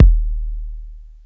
• Big Bass Drum Single Hit D# Key 310.wav
Royality free steel kick drum tuned to the D# note. Loudest frequency: 126Hz
big-bass-drum-single-hit-d-sharp-key-310-qRB.wav